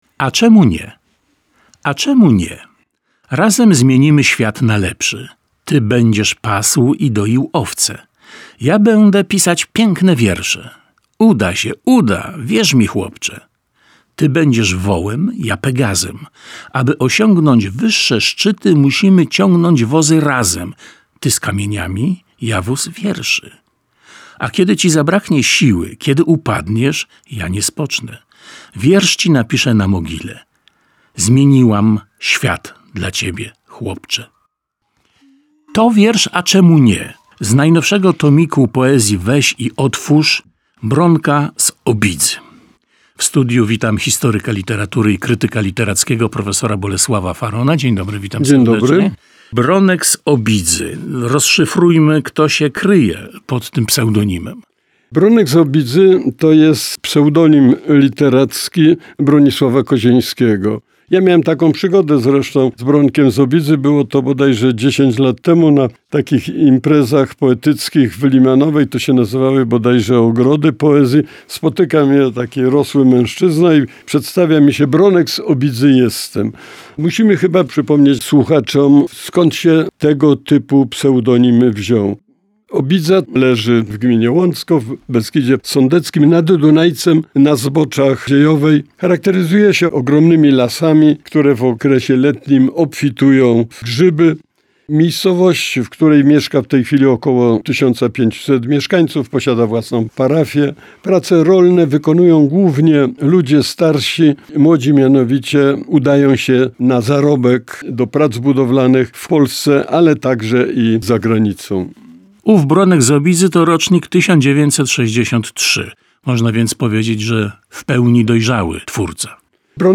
Zapraszamy także do posłuchania audycji "Pejzaże regionalne", która była nadawana na antenie Radia Kraków 11 lipca 2021 r. (godz. 7.05).